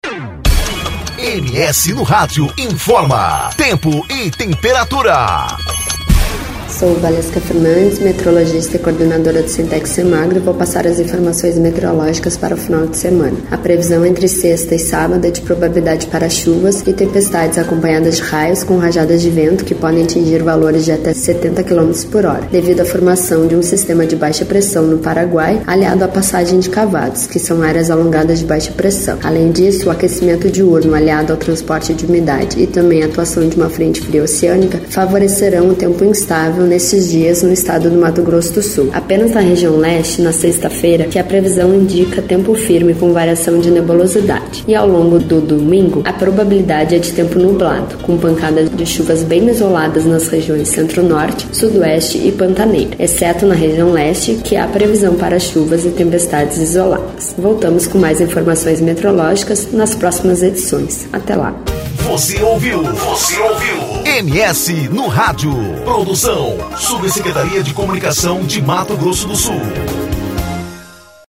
Previsão do Tempo: Chuvas e tempestades são esperadas para este final de semana